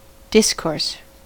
discourse: Wikimedia Commons US English Pronunciations
En-us-discourse.WAV